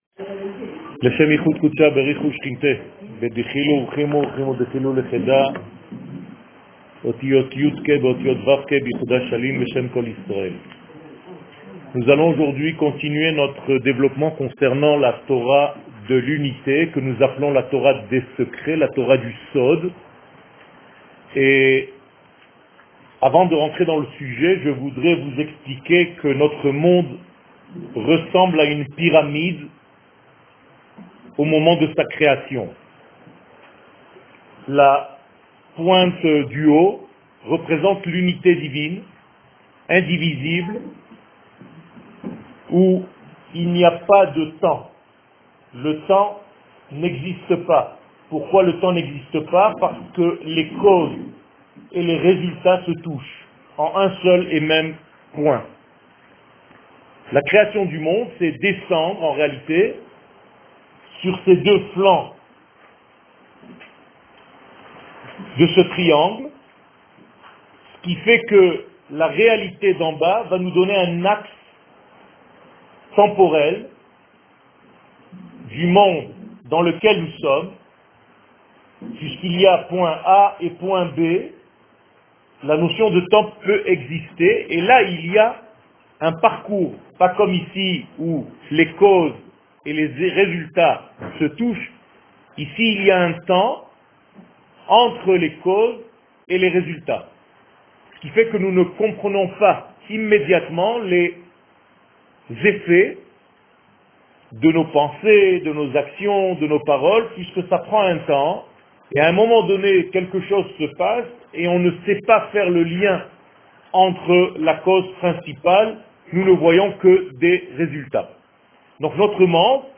שיעורים, הרצאות, וידאו